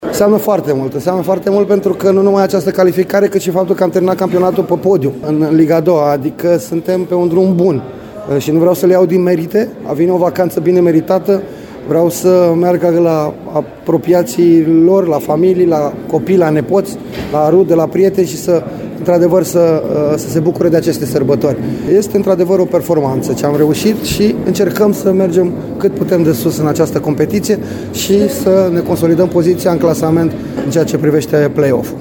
Antrenorul cărășenilor, Flavius Stoican, a vorbit despre semnificația calificării: